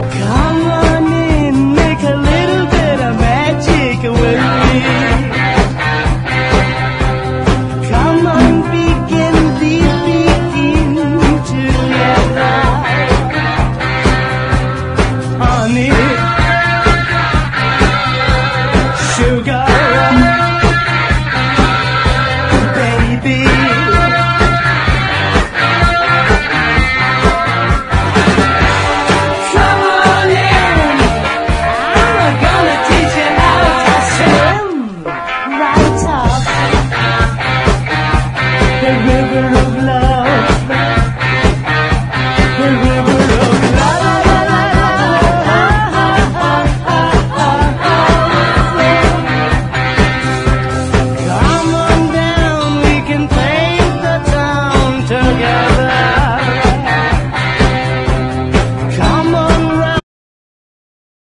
今でも通用するガレージーなインスト・オールディーズ！